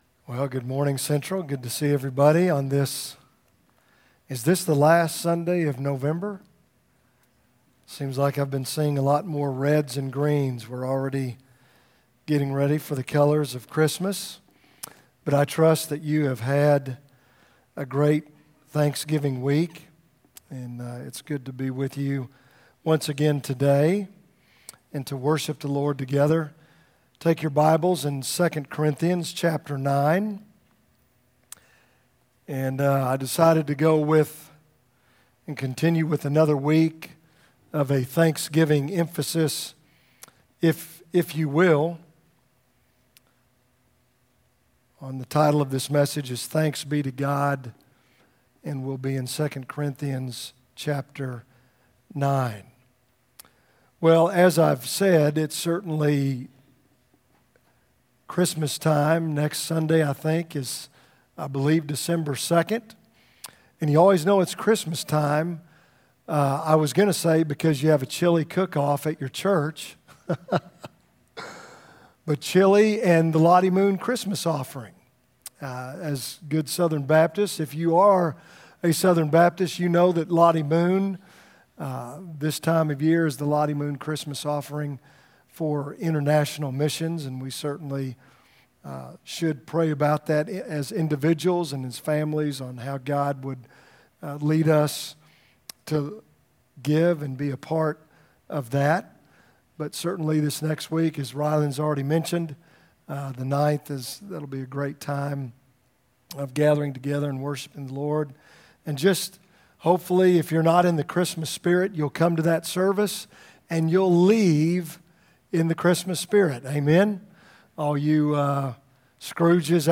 From Series: "2018 Sermons"